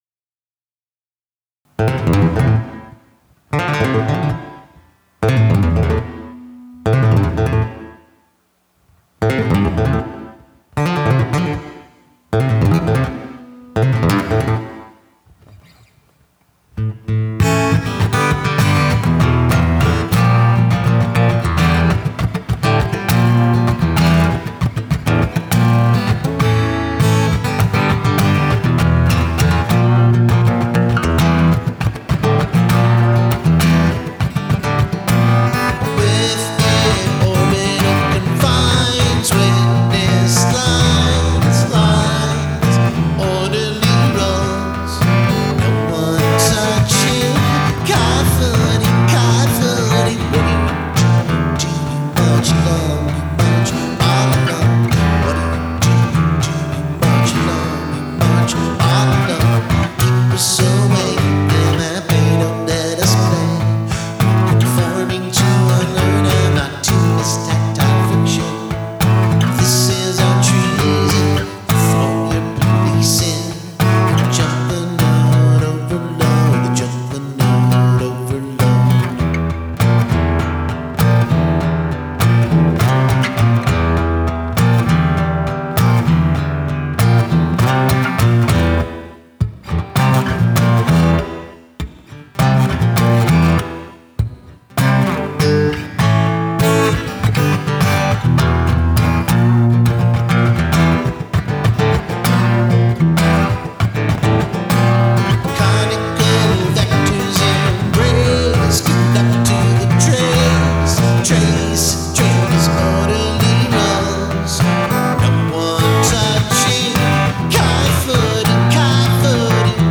Tempo: 100 bpm
Scratch || NonMaster